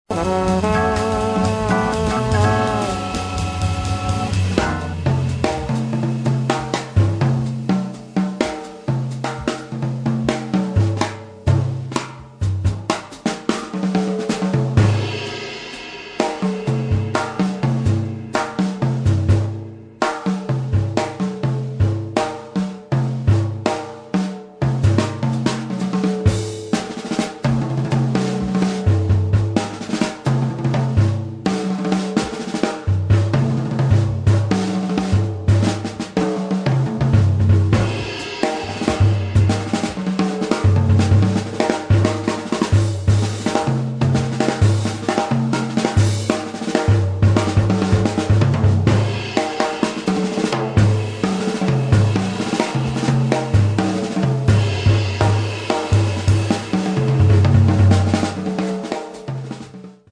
[ JAZZ ]